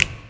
Grindin' Snap.wav